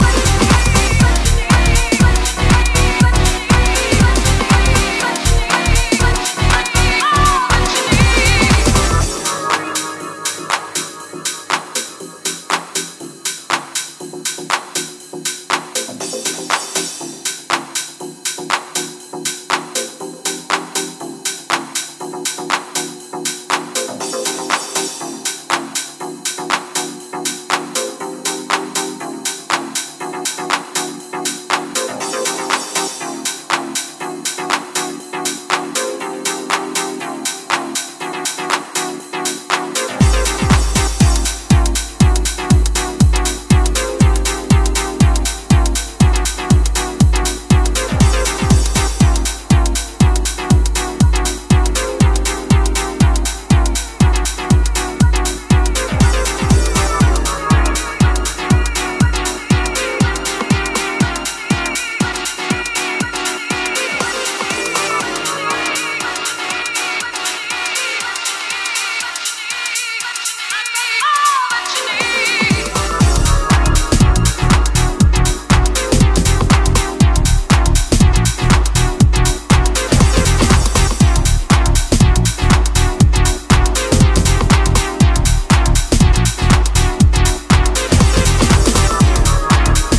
Effective stripped down techno tools.